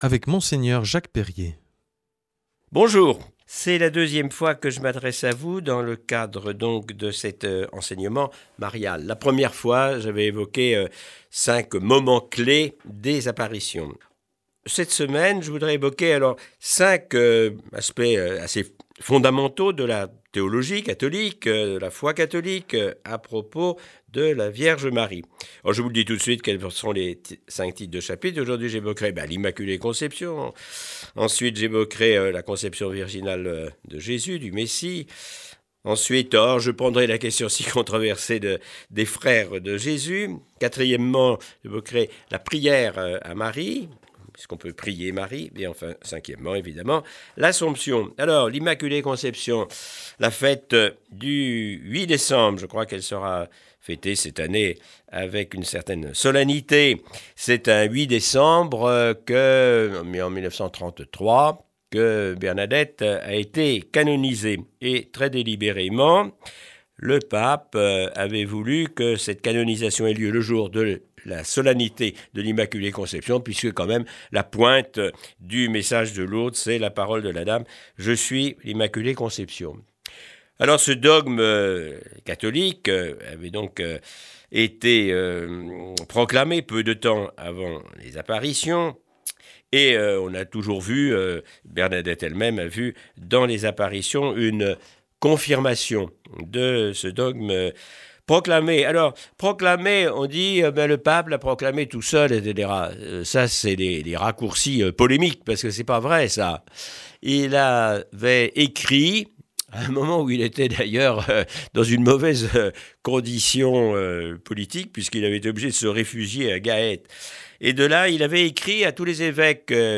Enseignement Marial du 16 oct.
Aujourd’hui avec Mgr Jacques Perrier.